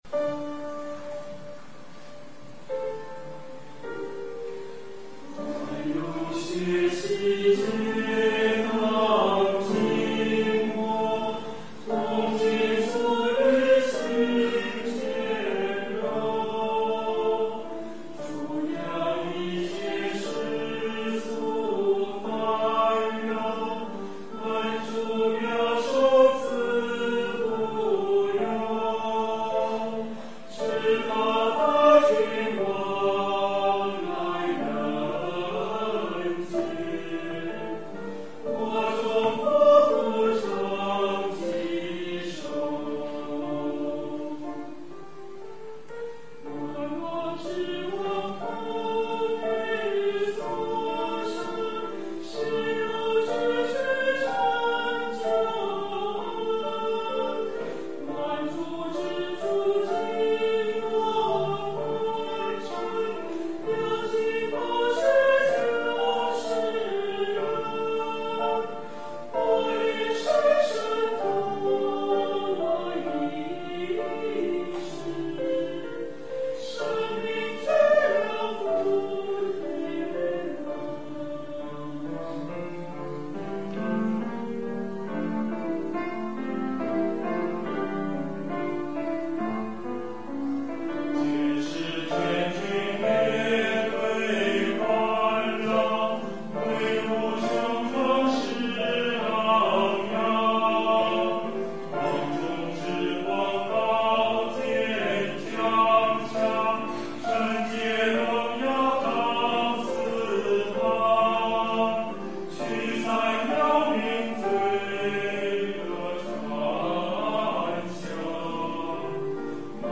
音频：剪辑自《上海国际礼拜堂圣诞颂唱》